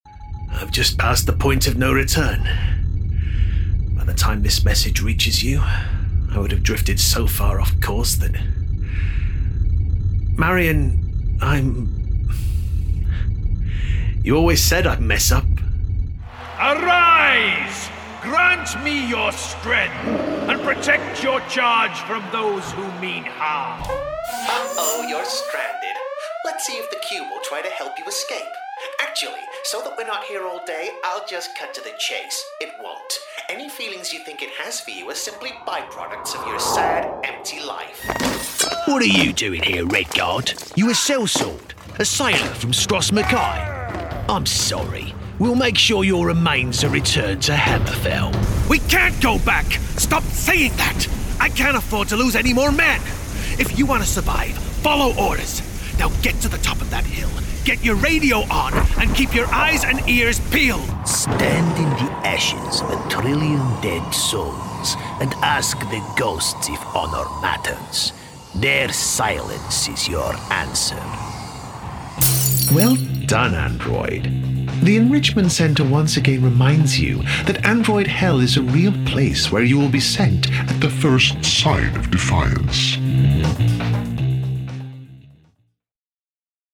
A Versatile British Voice
Games/Animation Demo Mix
... extremely versatile in his vocal range... his neutral voice has a soft tone, but with an air of authority...
A relaxed male voice over.